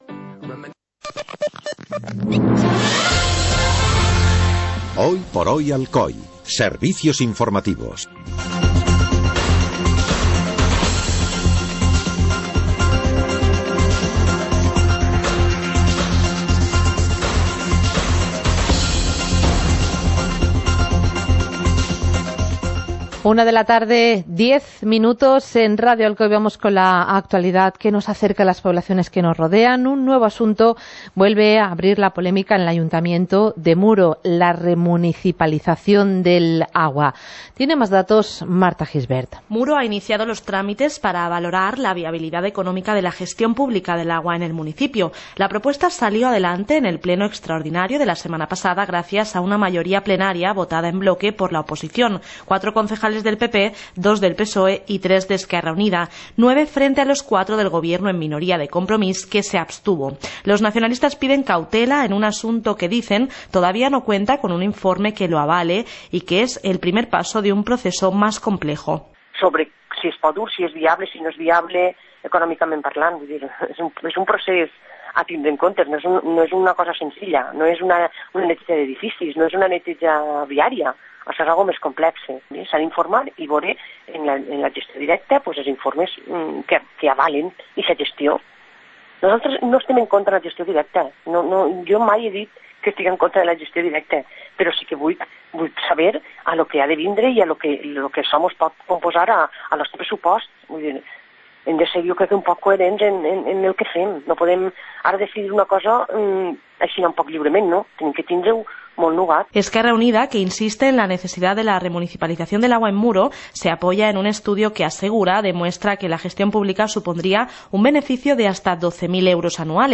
Informativo comarcal - martes, 24 de julio de 2018